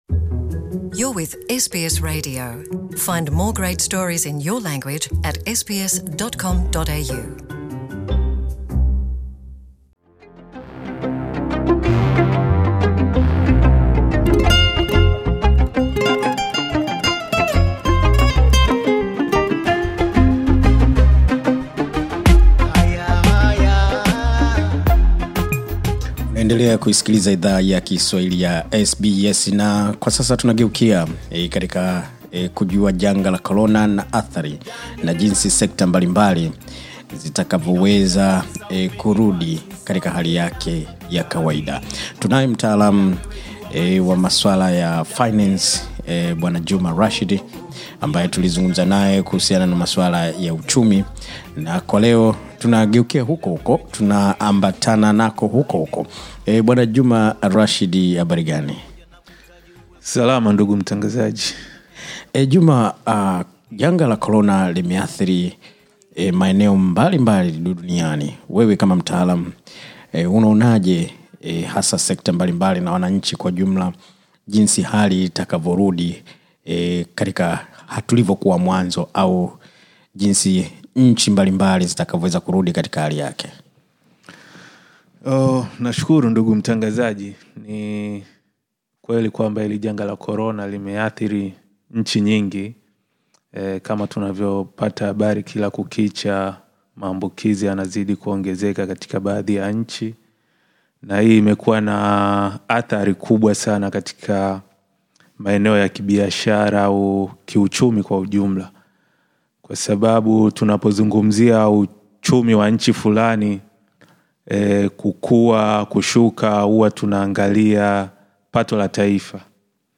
Haya hapa mazungumzo tuliyofanya na mtaalamu wa masuala ya fedha